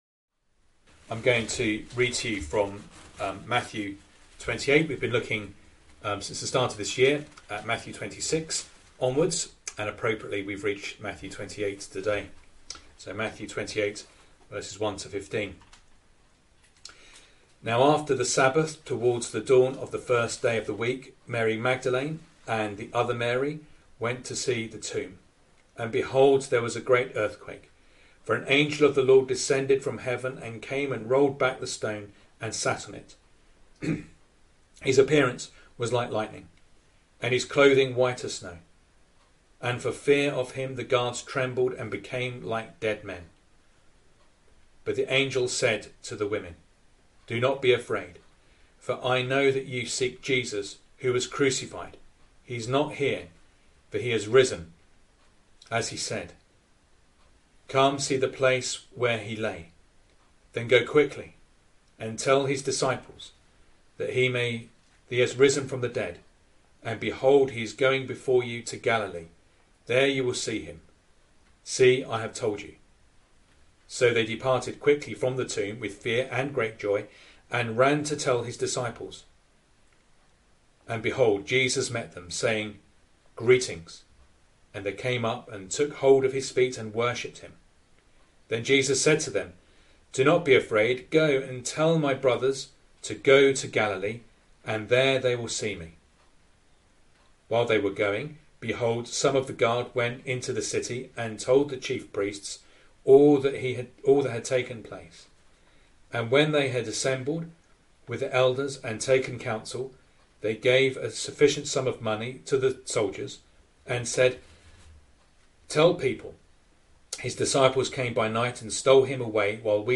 Service Type: Sunday Morning Reading and Sermon